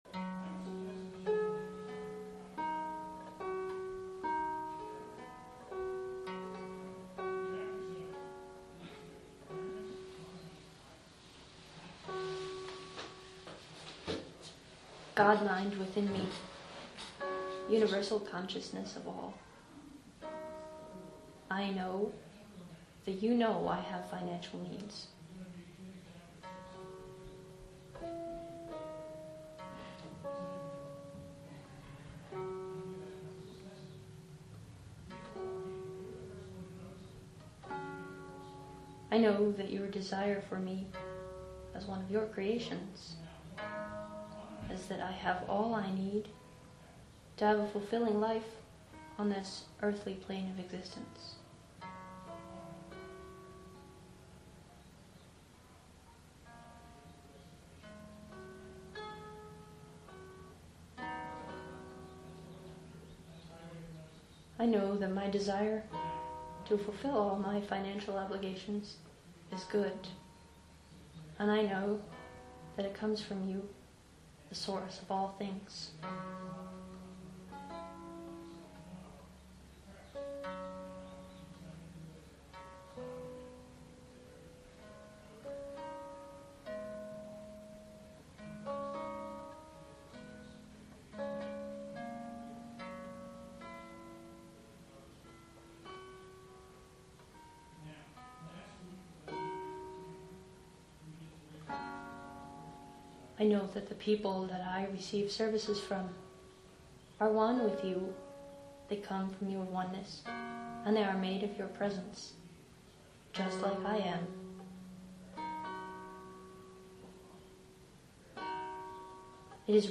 This treatment and the following one, are spontaneous, channeled prayers.
Right click and choose “Save target as…” or similar to download this treatment with harp accompaniment.